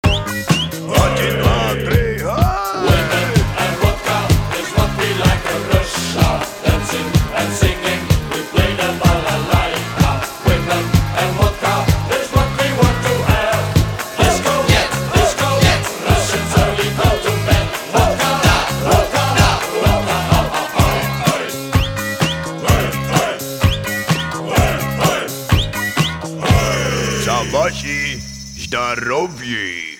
• Качество: 320, Stereo
забавные
веселые
с акцентом